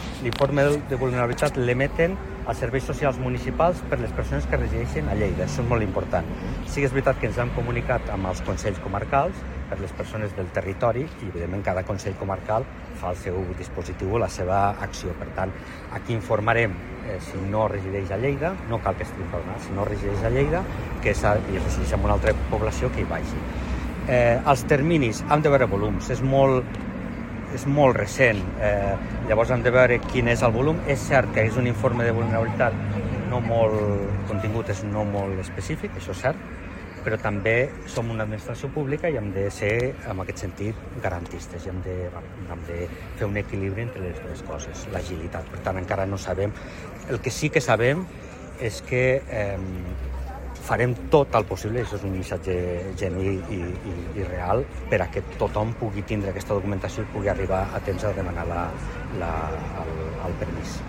Talls de veu
Tall de veu del paer en cap, Fèlix Larrosa, sobre el Sant Jordi a Lleida i diversos projectes culturals.